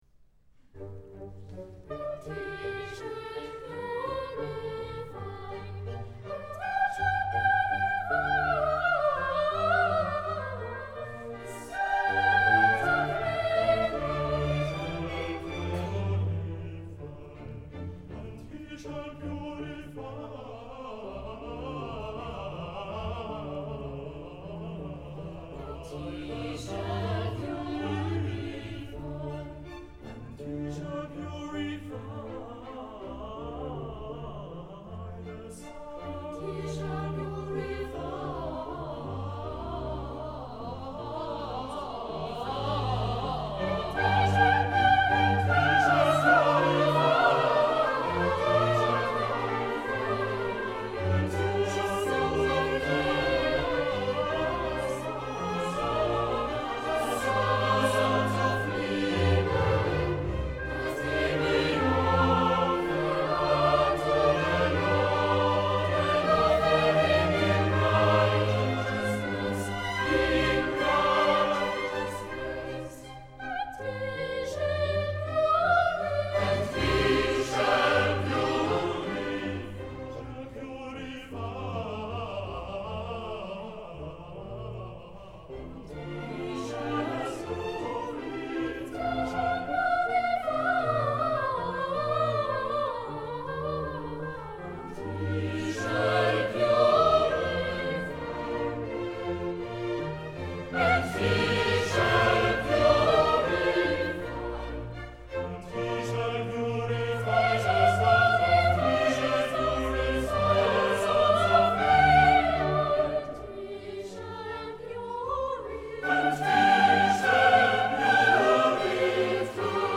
07-no-6_-chorus_-_and-he-shall-puri.mp3